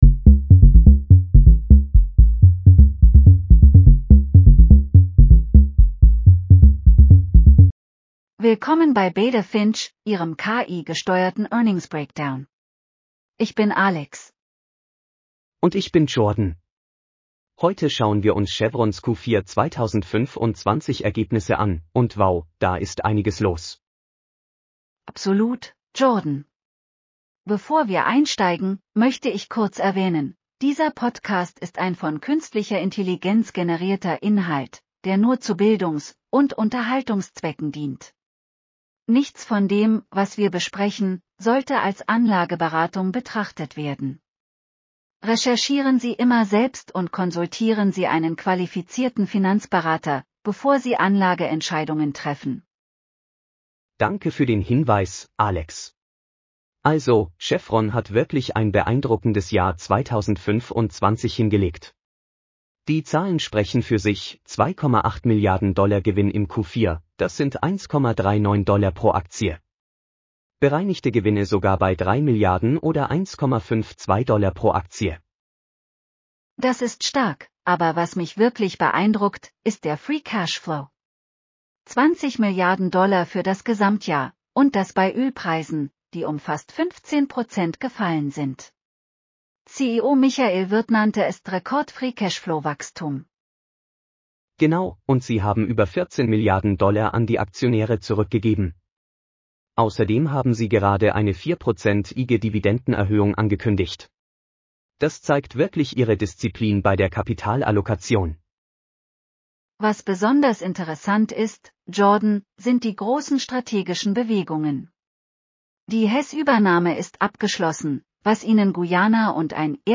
BETA FINCH PODCAST SCRIPT